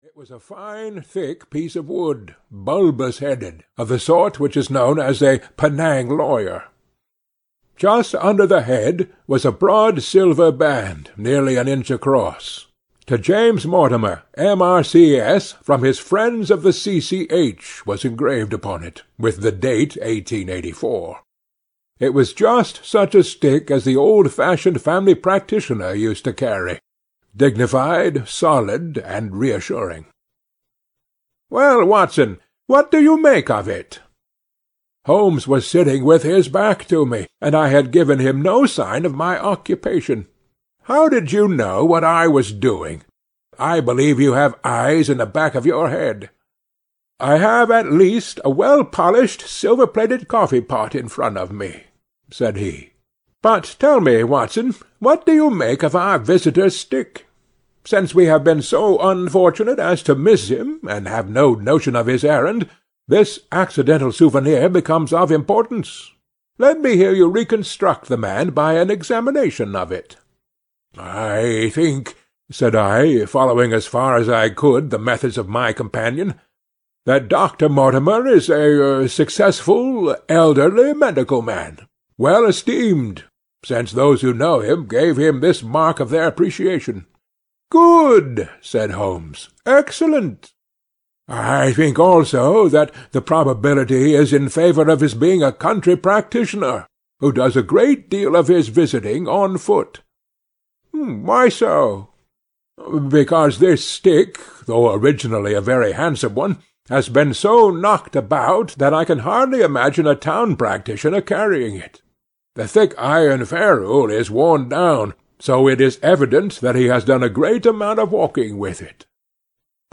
The Hound of the Baskervilles (EN) audiokniha
Ukázka z knihy